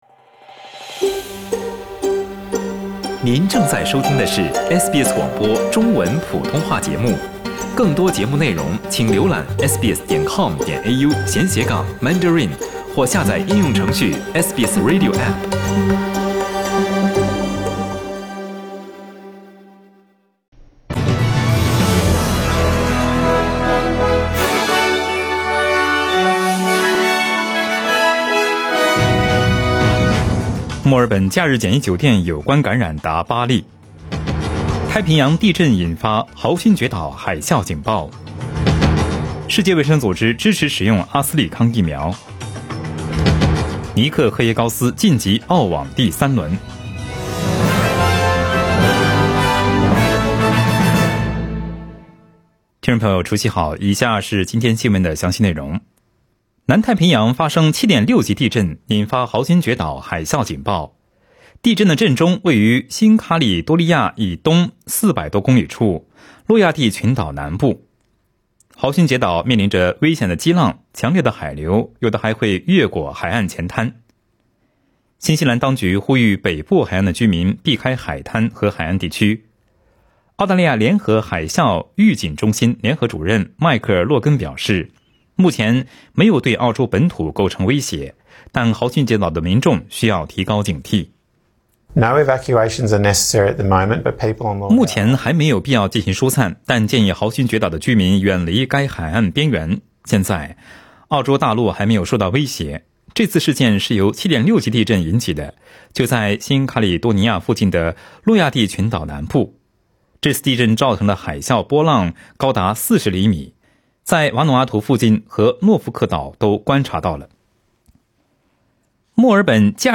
SBS早新聞（02月11日）